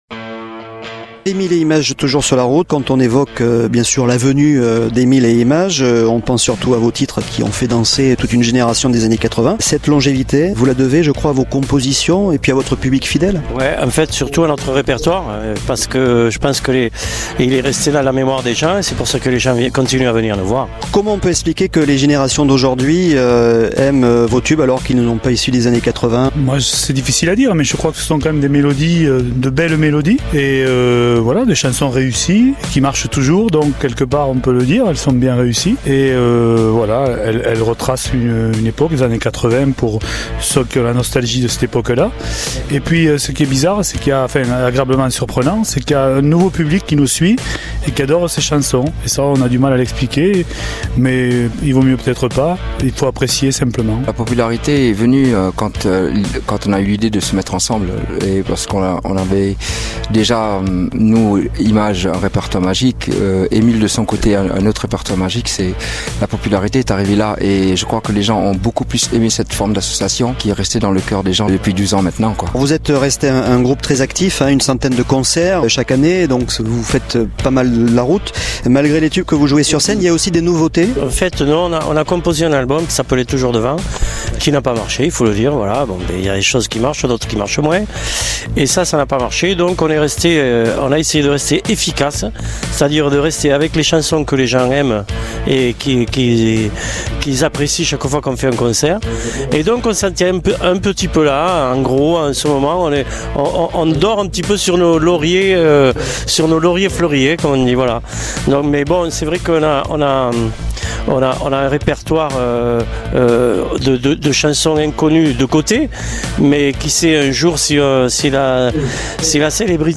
Interview Emile et Images sur Radio Grand "R"